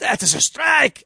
MrPin04-strike.wav